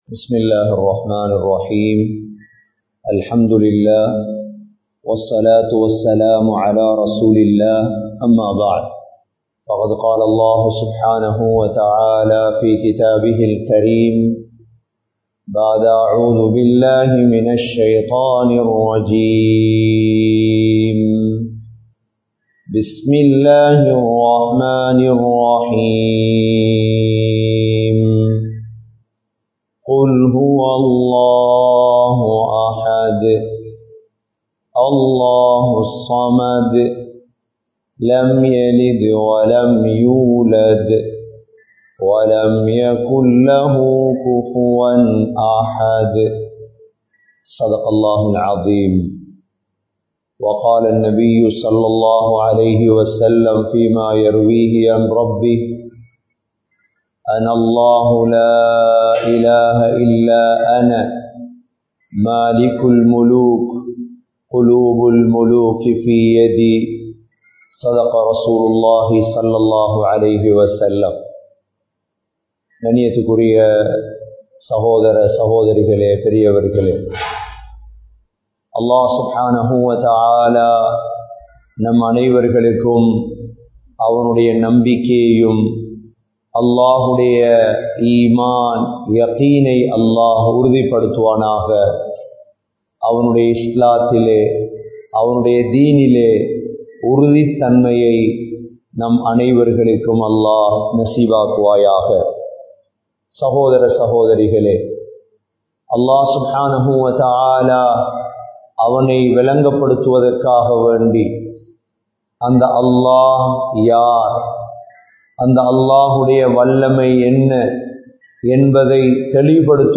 05 Vidayangal (05 விடயங்கள்) | Audio Bayans | All Ceylon Muslim Youth Community | Addalaichenai
Live Stream